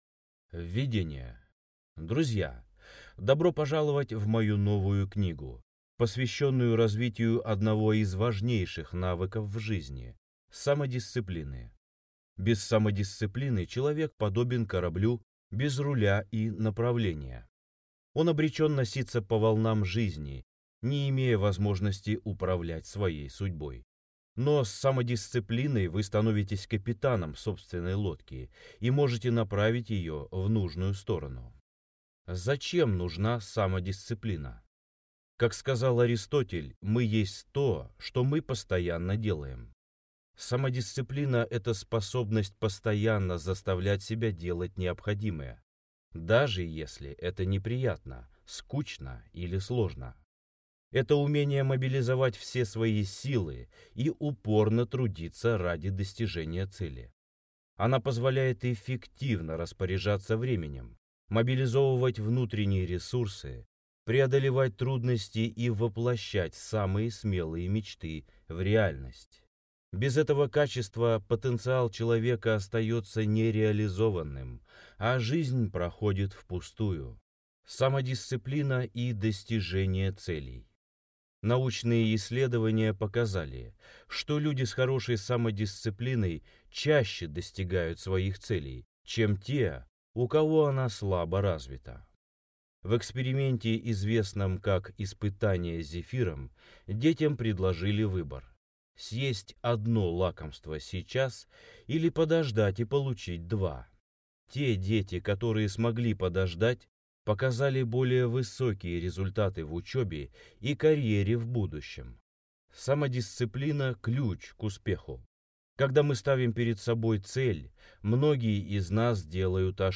Аудиокнига Самодисциплина. Как доводить начатое до конца, не останавливаться на полпути к цели и добиваться желаемого за 30 дней. Книга-тренинг | Библиотека аудиокниг